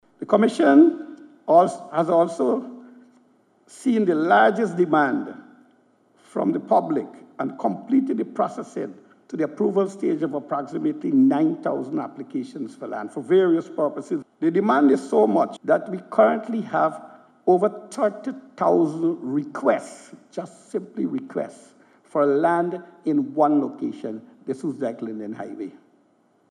His made this comment during a keynote address at the closing ceremony of the Sustainable Land Development and Management Project at the Pegasus Suites.